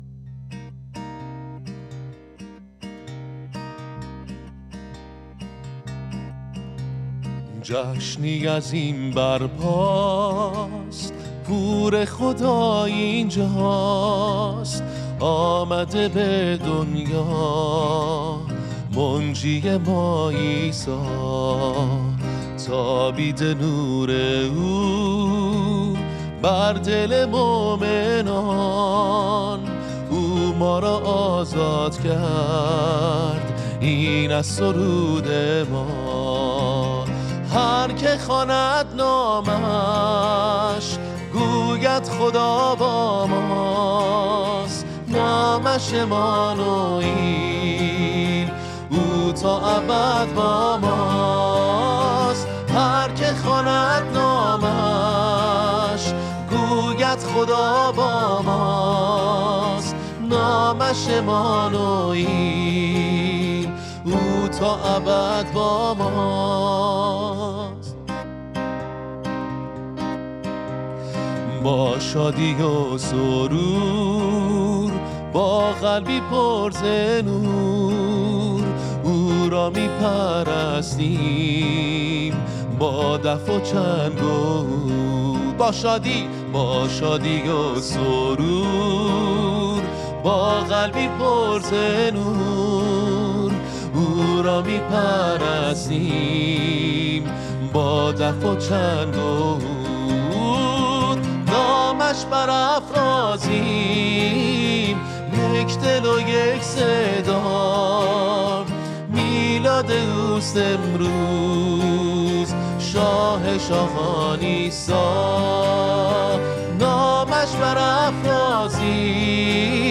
مینور
Minor